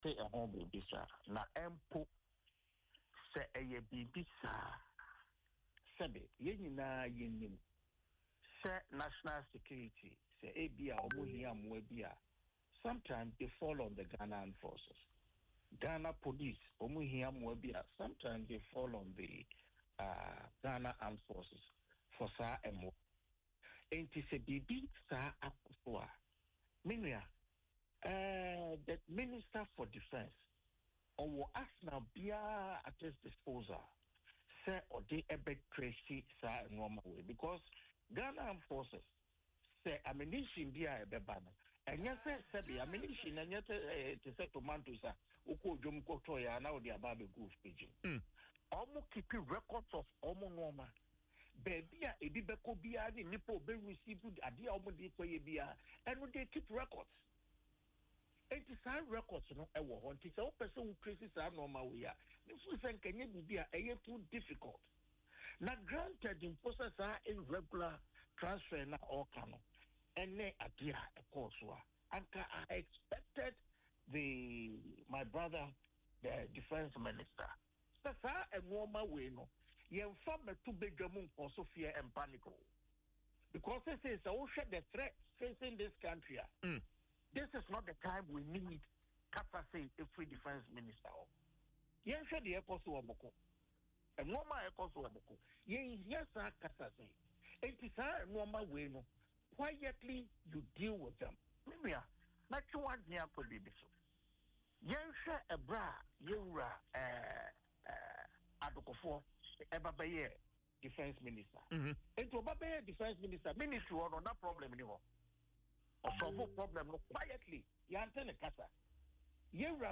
Speaking in an interview on Adom FM’s Dwaso Nsem, the Atwima Mponua MP argued that ammunition, unlike consumables, goes through strictly regulated processes that ensure proper oversight and prevent misuse.
Kofi-Amankwa-Manu-on-ammunition-.mp3